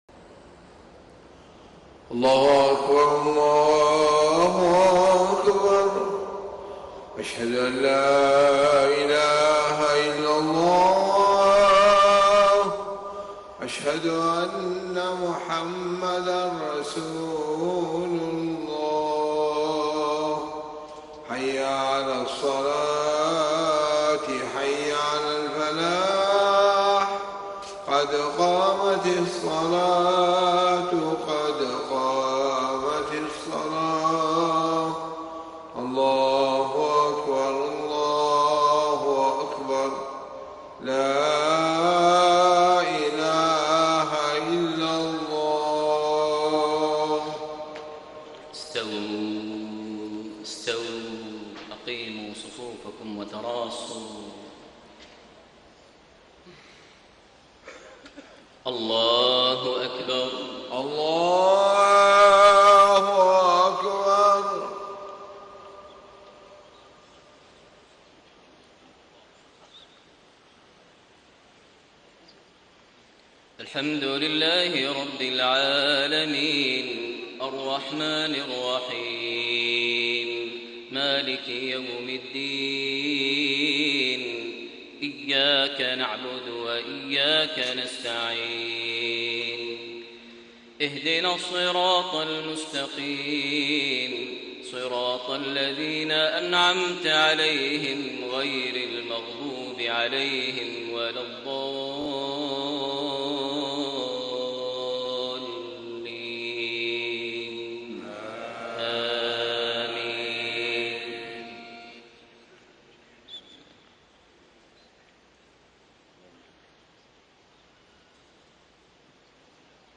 صلاة العشاء 6 - 1 - 1435هـ من سورة الرحمن > 1435 🕋 > الفروض - تلاوات الحرمين